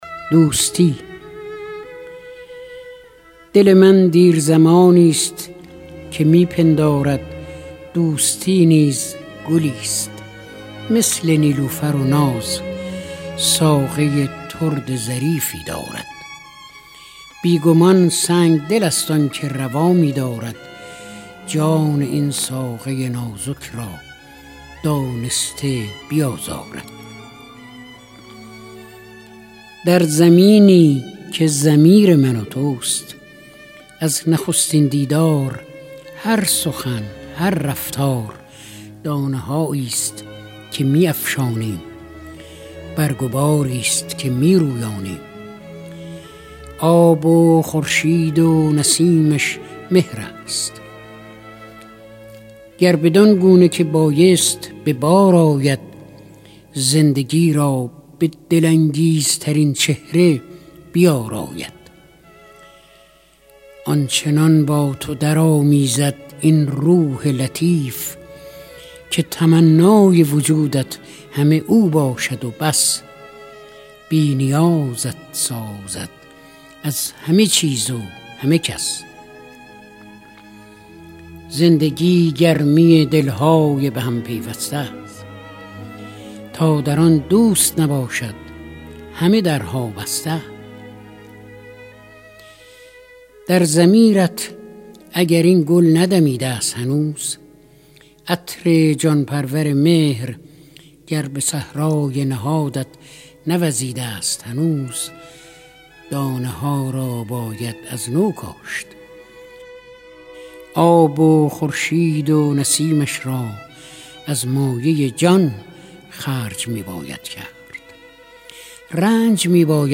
دانلود دکلمه دوستی با صدای فریدون مشیری با متن دکلمه
گوینده :   [فریدون مشیری]